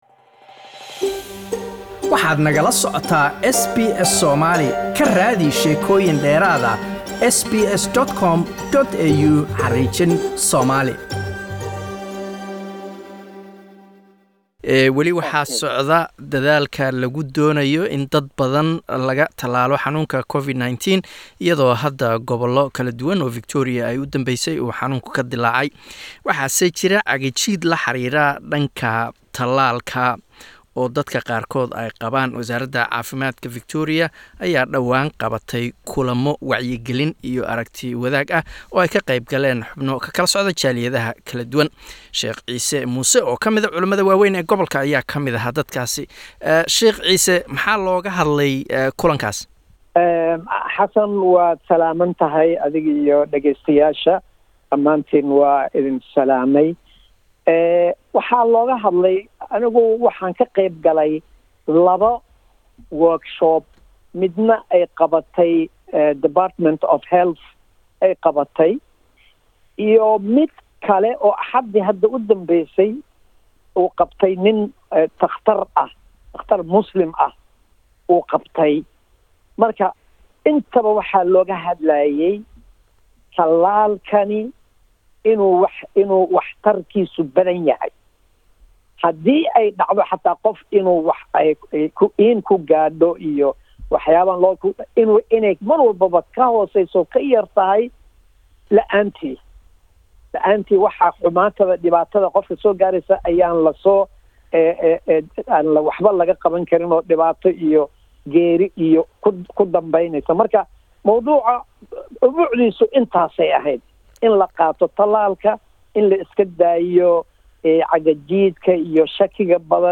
Waxaa socda dadaalo lagu doonayo in dadka lagu wacyigaliyo si ay u qaataan talaalka COVID-19 ee dalkan Australia. Haddaba waxaan doorka culumada ka waraysanay